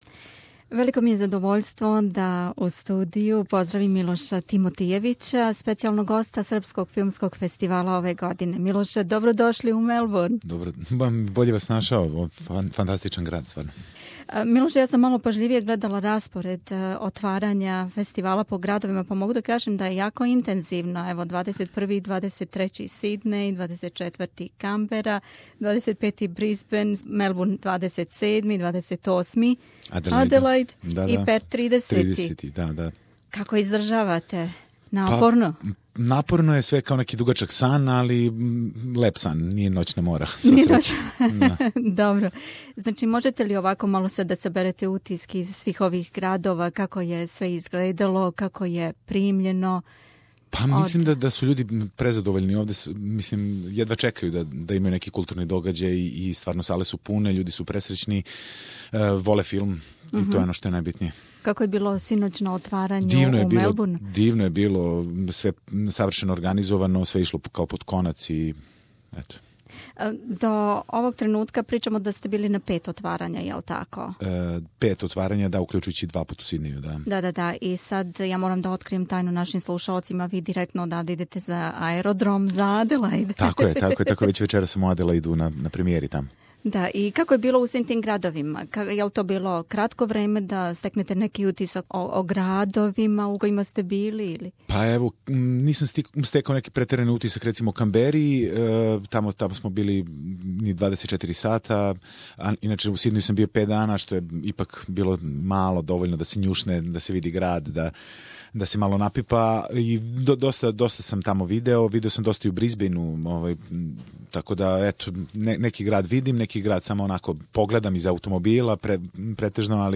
Милош Тимотијевић је специјални гост Српског филмског фестивала у Аустралији. На међунарподном филмском фетивалу у Београду ФЕСТ2016 Милош је проглашен за најбољег глумца фестивала а филм "Влажност" најбољим домаћим остварењем. Током отварања 16-ог СФФ у свим већим градовима Аустралије имате шансу да упознате и попричате са Милошем .... а после отварања у Мелбурну и пре пута у Аделајд Милош је свратио у студио СБС радија...